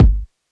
Kick (30).wav